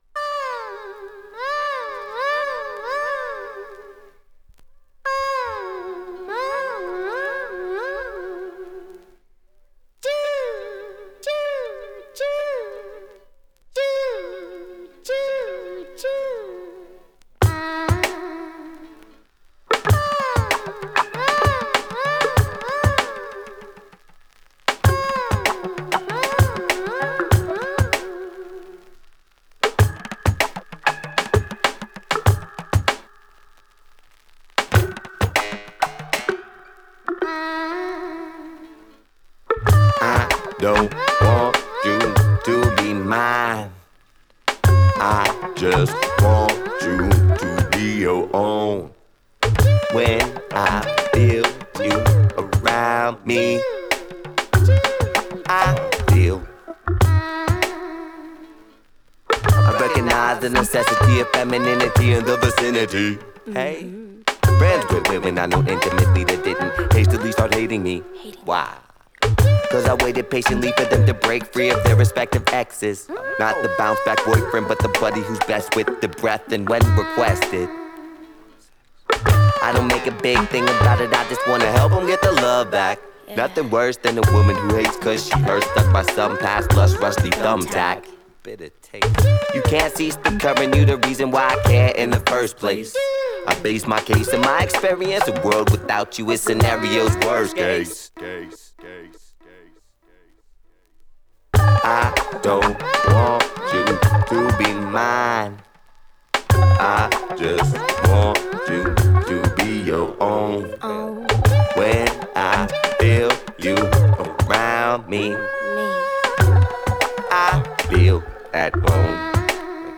アラブ風コーラスとパーカッションがエスニックな雰囲気を盛り上げ